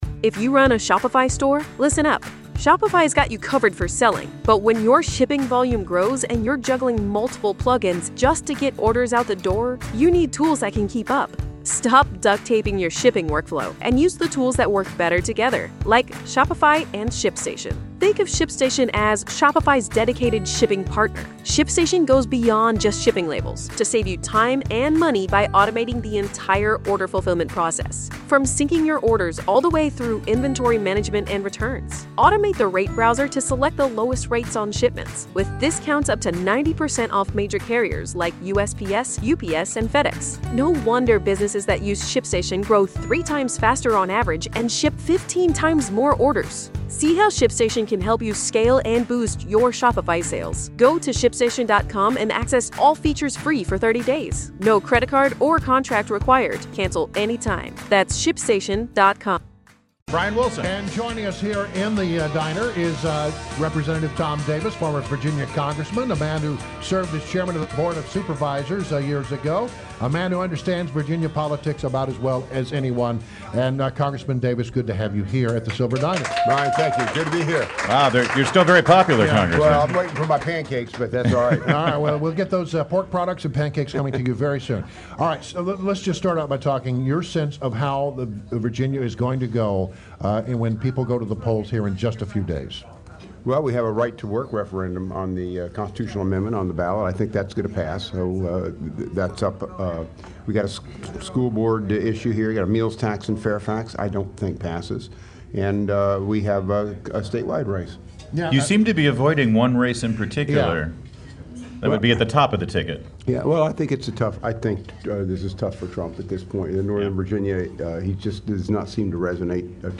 WMAL Interview - REP. TOM DAVIS - 10.21.16